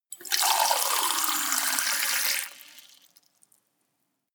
Gemafreie Sounds: Gastronomie